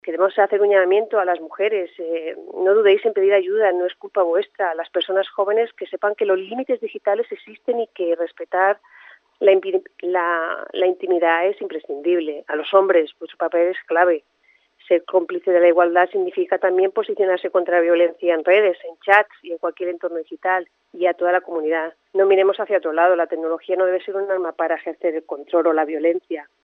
Remedios Márquez, regidora d'Igualtat